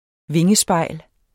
vingespejl substantiv, intetkøn Bøjning -et, -e, -ene Udtale [ ˈveŋəˌsbɑjˀl ] Betydninger 1.